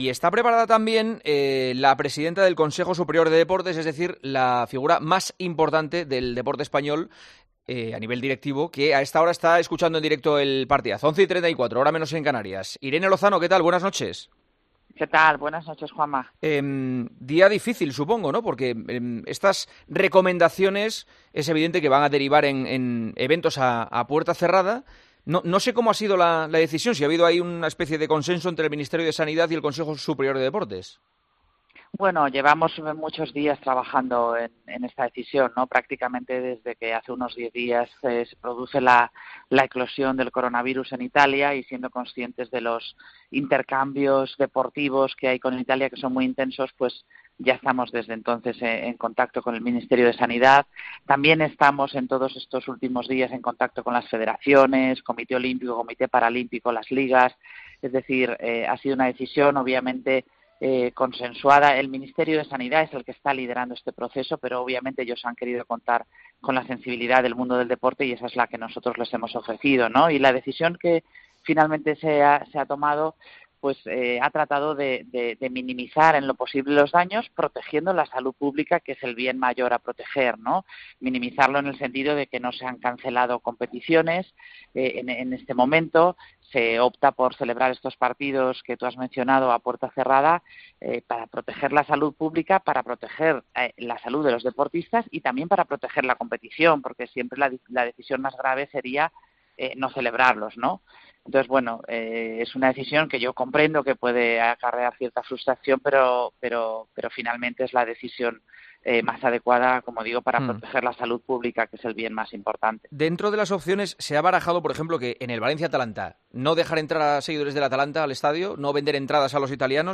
La presidenta del CSD, Irene Lozano , atendió este martes la llamada de El Partidazo de COPE , con Juanma Castaño , para aclarar por qué el Ministerio de Salud ha tomado las medidas que ha anunciado este martes y que afectan a cuatro partidos, en los que se jugará sin público, para evitar la presencia de aficionados italianos de las zonas más afectadas por el coronavirus.